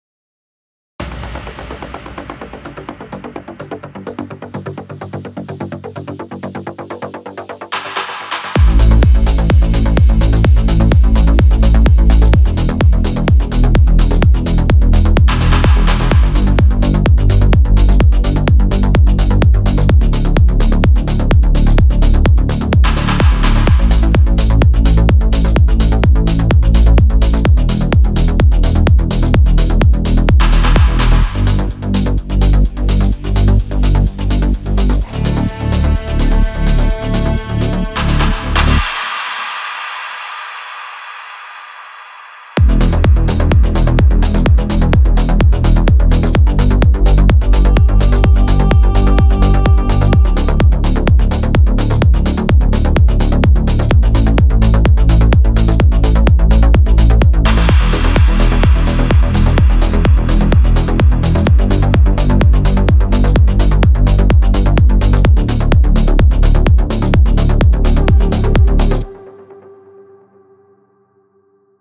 Trance песочница (крутим суперпилы на всём подряд)
Model 72, вариант без ревера и делея и с ревером и пленкой Не очень близко, но по мотивам:) Надо еще с параметрами видимо поварьировать